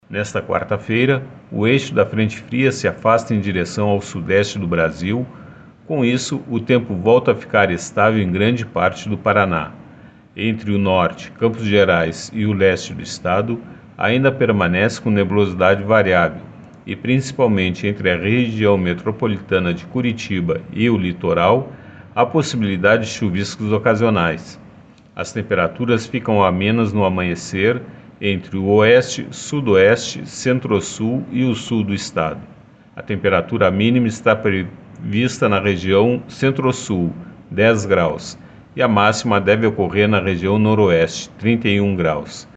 Previsão